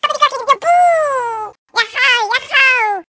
One of Yoshi's voice clips in Mario Kart 7